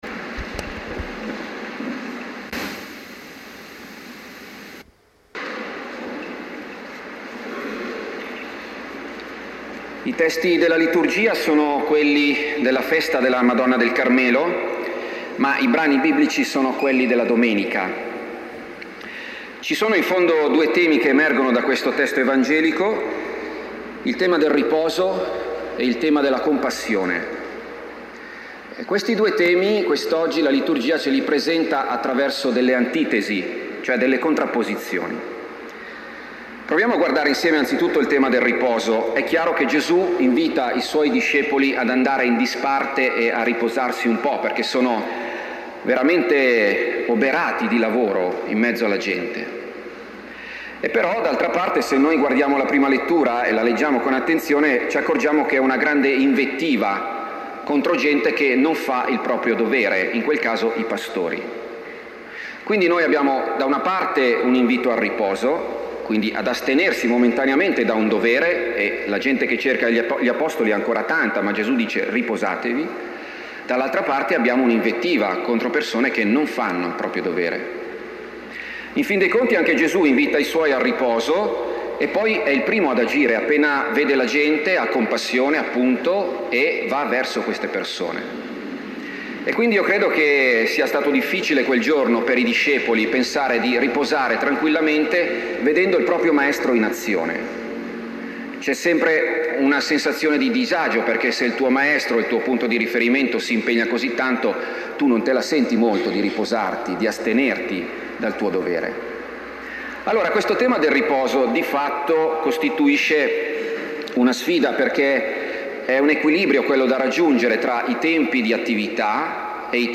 Omelia domenica 19 luglio – Parrocchia di Roveleto
Omelia-domenica-19-luglio.mp3